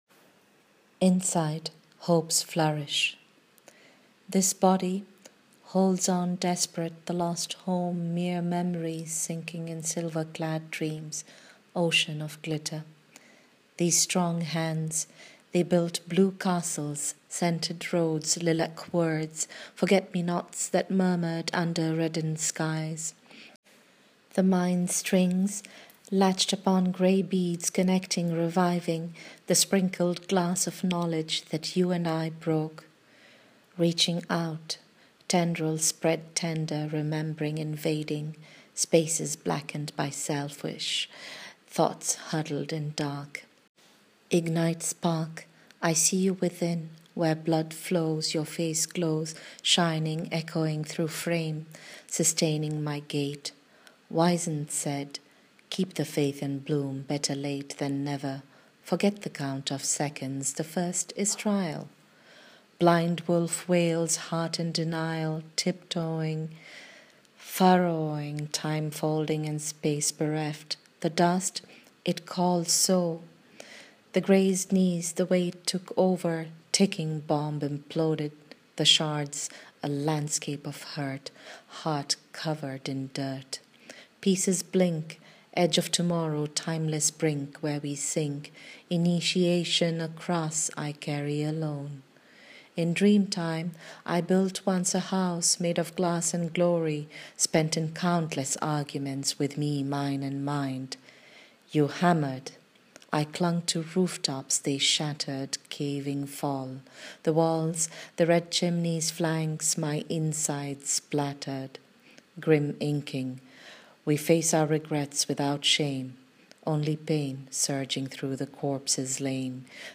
Reading of the poem :